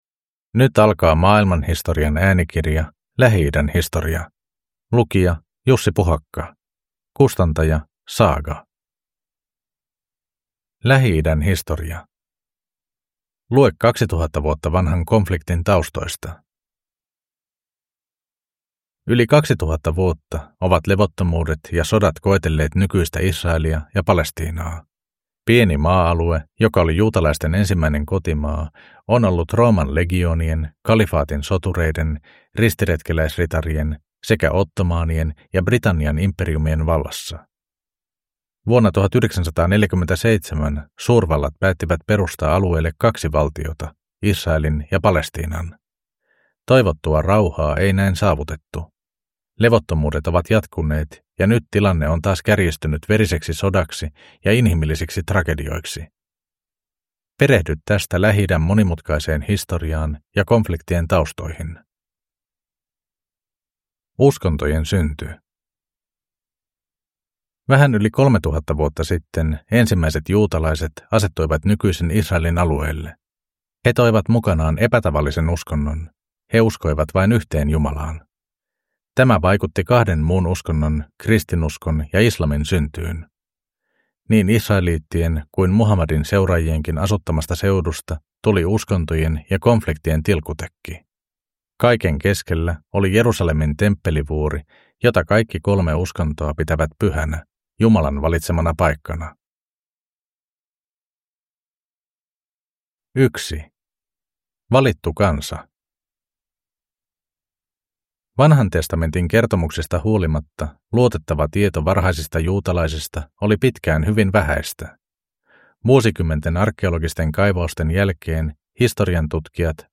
Lähi-idän historia – Ljudbok